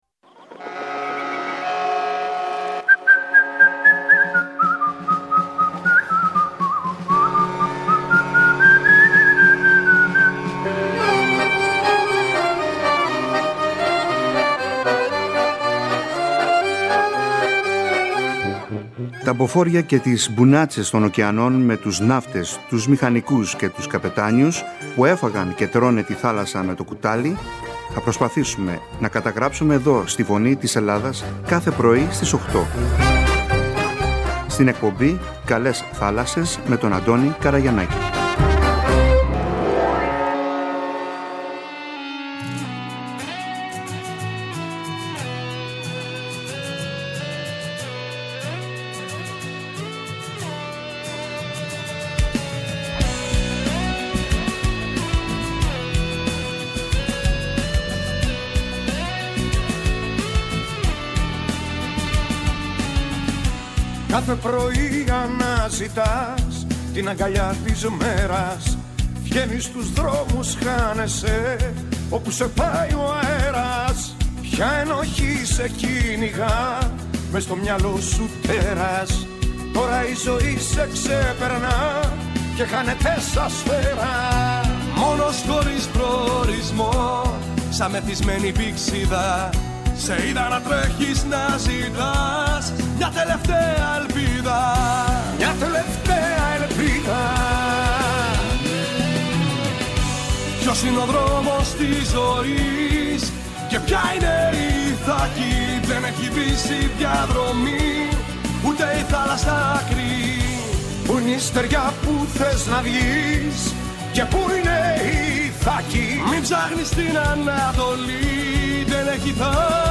Εκπομπή για τους Έλληνες ναυτικούς με θέματα που απασχολούν την καθημερινότητά τους. Ιστορίες και μαρτυρίες ναυτικών και ζωντανές συνδέσεις με τα ελληνικά ποντοπόρα πλοία που ταξιδεύουν στις θάλασσες του κόσμου.